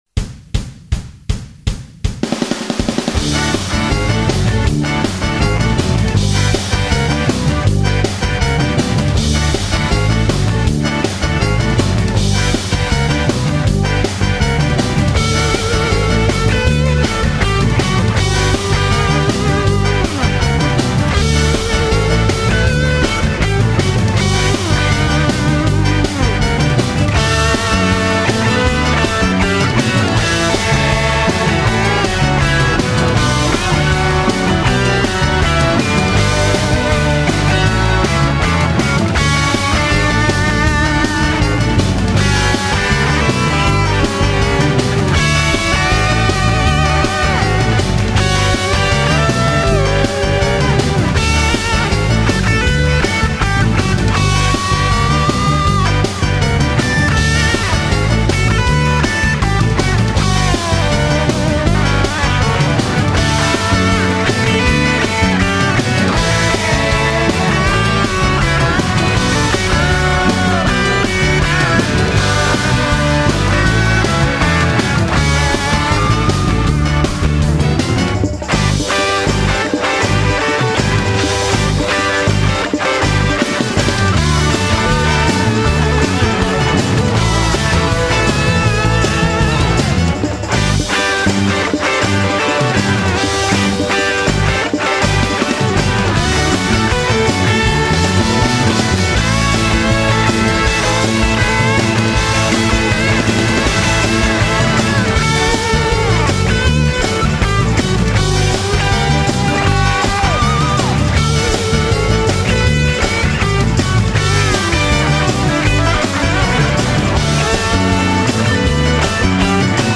punk/new wave
In this fast ska version